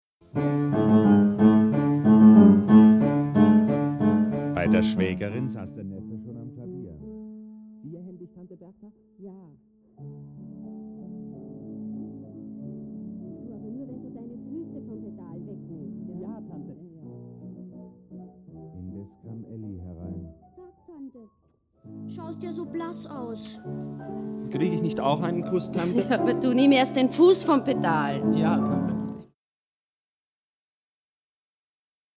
Die folgenden Hörbeispiele simulieren, wie sich der Hörkrimi „Brändles Nichte“ mit Tinnitus, leichter, mittel- oder hochgradiger Hörminderung anhören würde. Sie erklingen zuerst normal und blenden dann den Effekt der Hörminderung ein.
Mittelgradige Schwerhörigkeit
3-Mittelgradige-Schwerhoerigkeit.mp3